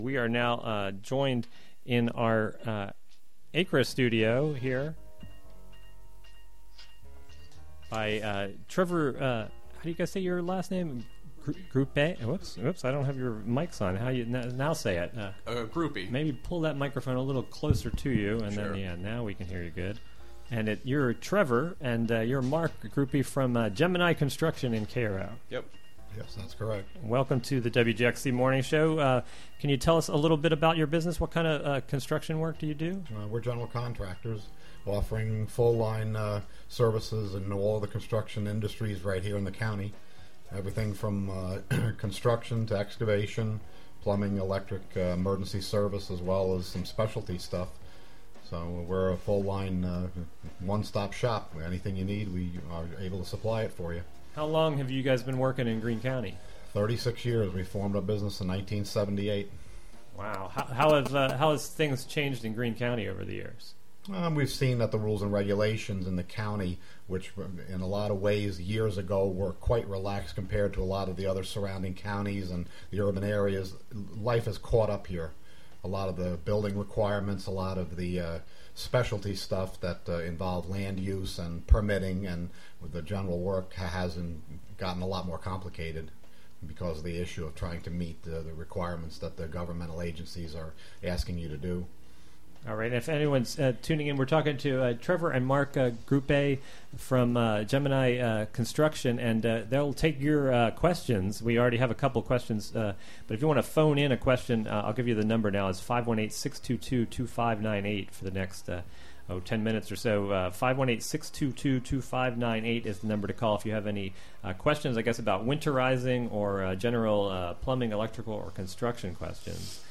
10am Local interviews, local news, regional event previ...